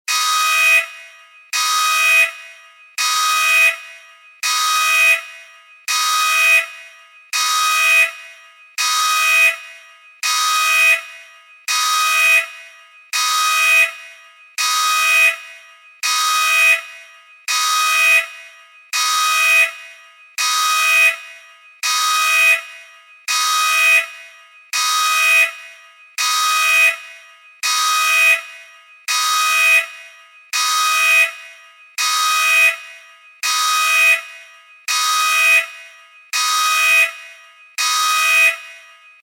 Nhạc Chuông Báo Động Tàu Vũ Trụ